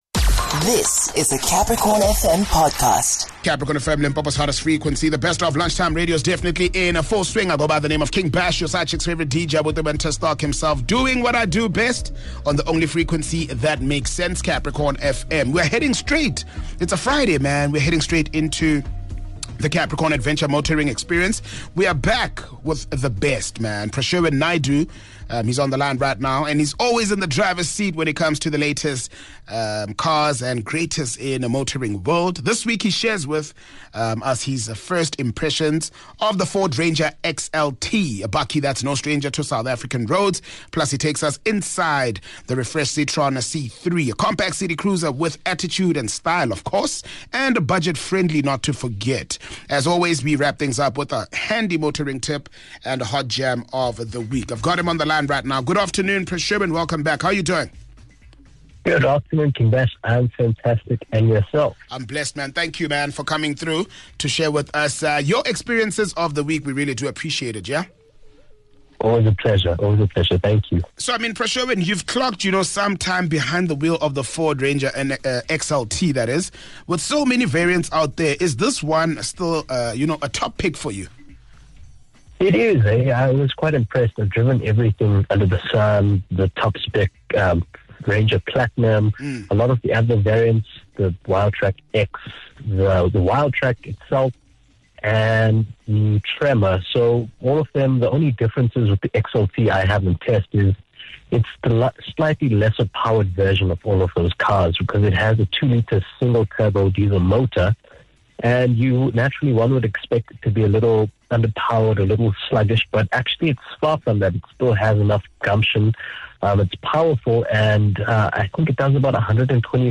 joined on the line by motoring journalist